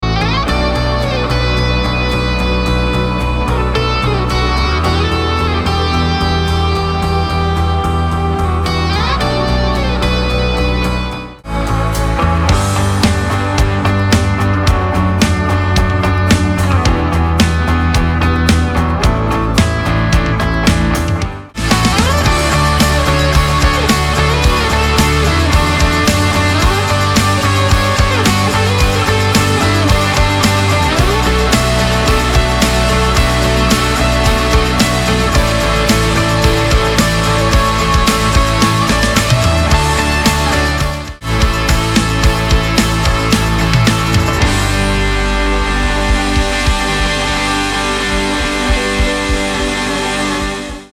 • Compás: 4/4
• Tono: D
• BPM: 110
• Drums
• Bass
• Guitarra eléctrica 1-2-3-4-5
• Guitarra acústica
• Hammond
• Piano
• Synth bass